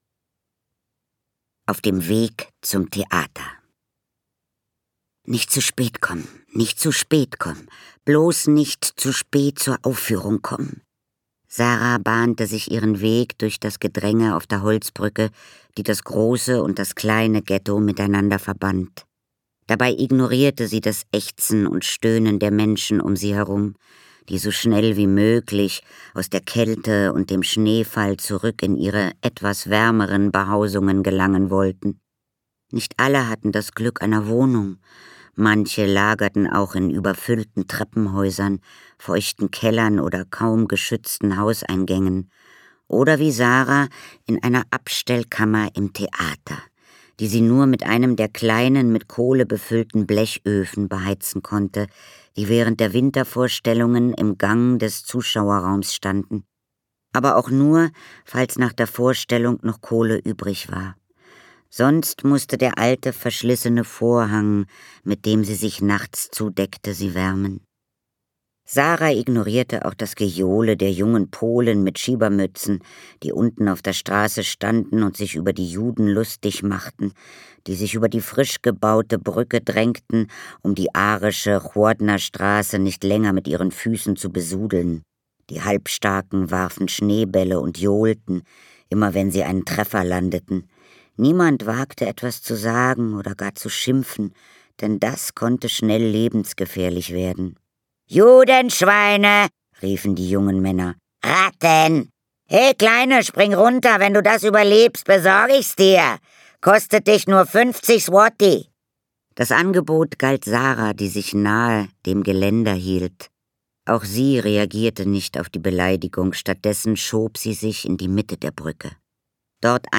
Katharina Thalbach (Sprecher)
2025 | 1. Auflage, Ungekürzte Ausgabe